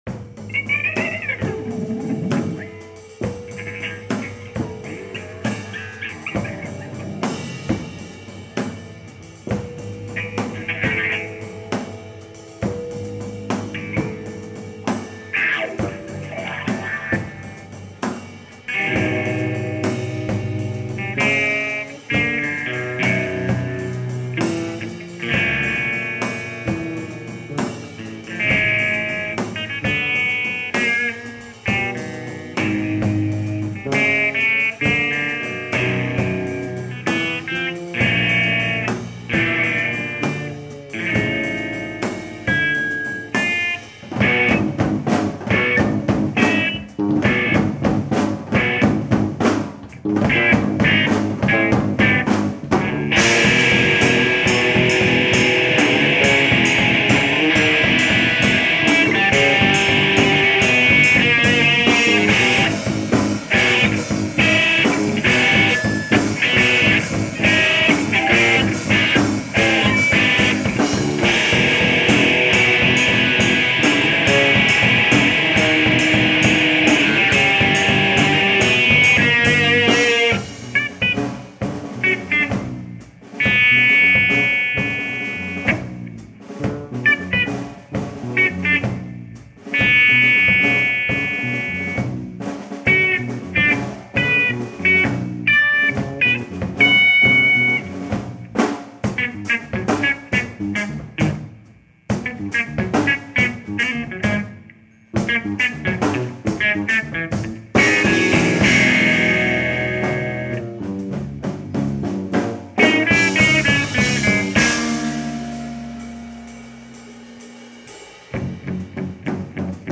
(Live and dirty at the Starry Plough October 11, 2003)
Live recordings: